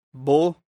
Výslovnost a pravopis